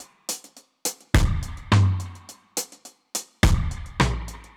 Index of /musicradar/dub-drums-samples/105bpm
Db_DrumsB_Wet_105-02.wav